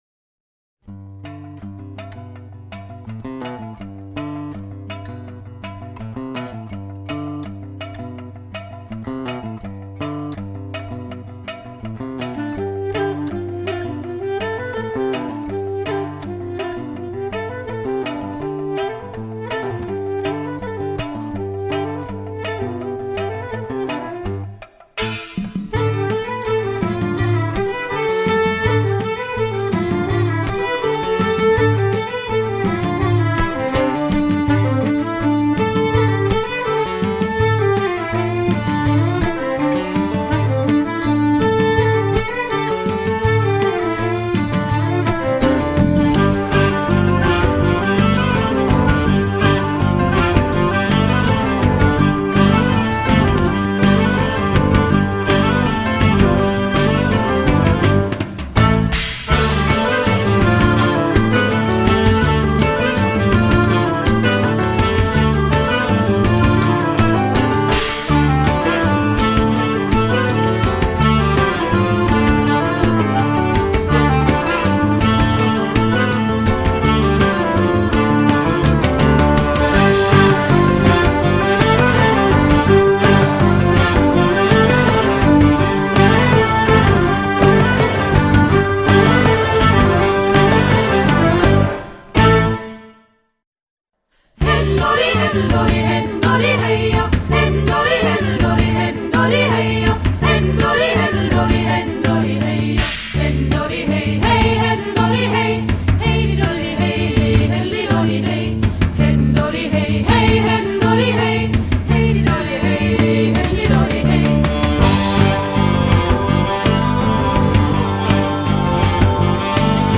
融合古典、摇滚、北欧乡村风、印度民族风、苏格兰曲风、柔情女声、吟游诗歌..等多元乐风
录音定位清楚、音质细腻清晰、音像深度及宽度精采可期
透过人声、小提琴、萨克斯风、双簧管、贝斯、吉他、钢琴、打击乐器、印度笛、竖笛、扬琴、